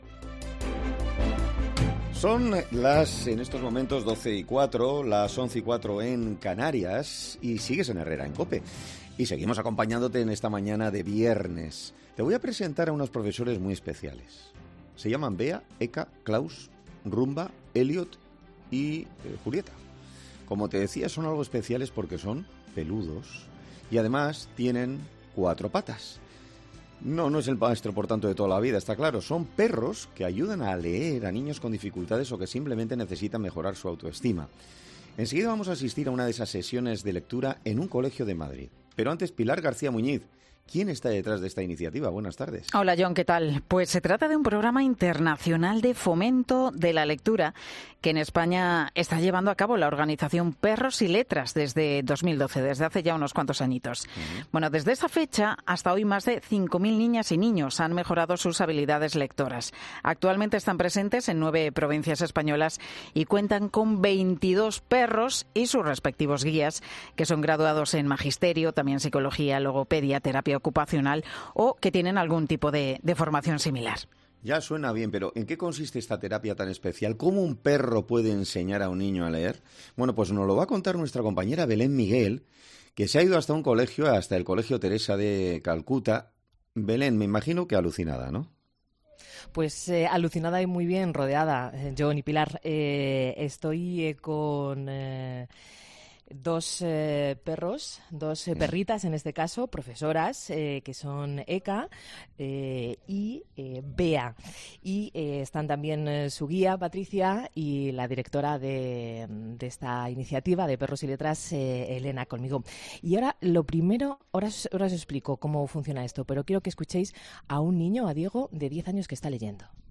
En 'Herrera en COPE' hemos asistido una de estas sesiones de lectura en un colegio de Madrid.